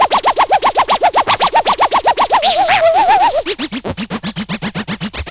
MOOG_SCRATCHES_0002.wav